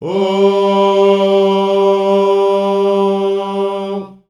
Index of /90_sSampleCDs/Voices_Of_Africa/LongNoteSustains
18_05_Ooo_G.WAV